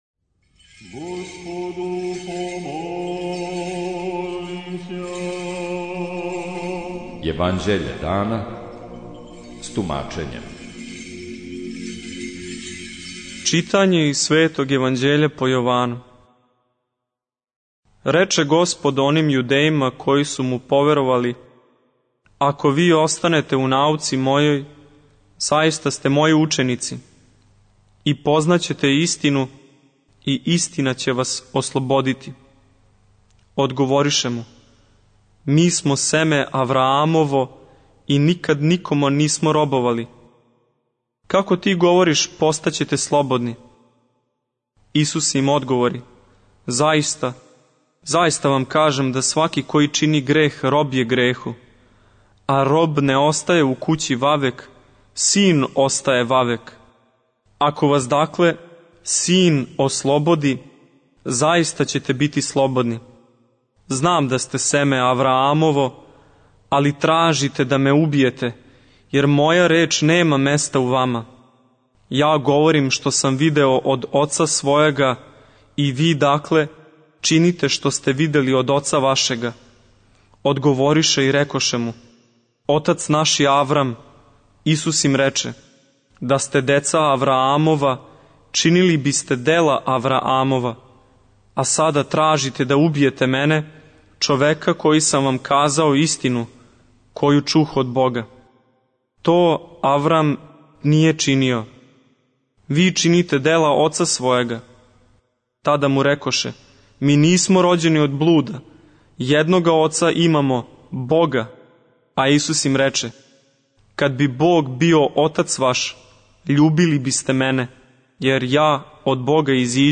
Читање Светог Јеванђеља по Луки за дан 18.11.2023. Зачало 40.